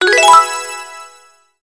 shake_match.wav